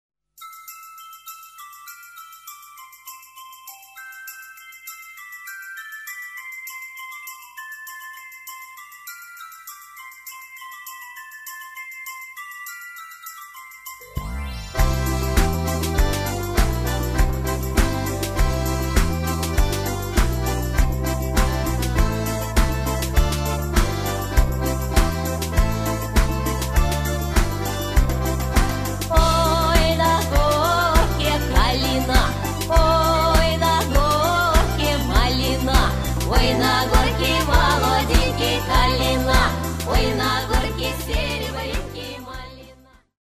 РУССКОГО ФОЛЬКЛОРНО-ЭСТРАДНОГО АНСАМБЛЯ